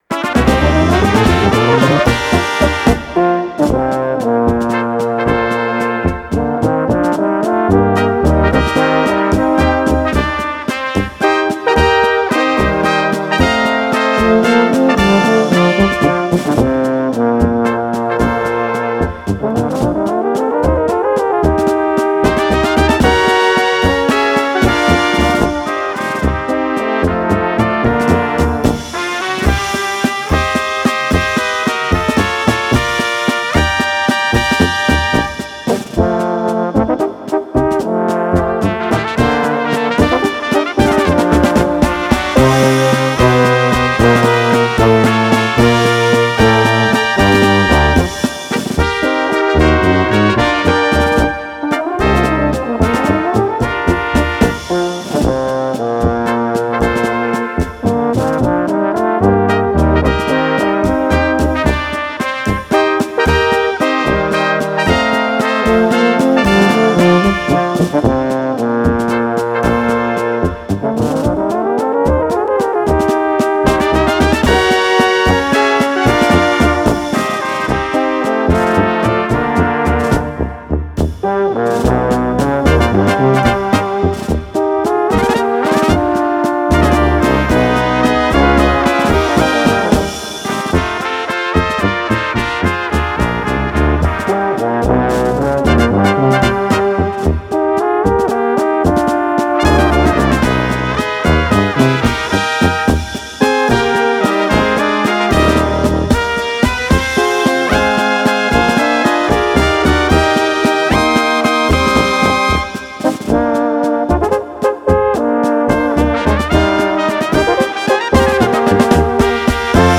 Gattung: Walzer für Böhmische Besetzung
1.-3.Trompete in B
Tenorhorn B
Bariton B/C
Tuba C/B
Schlagzeug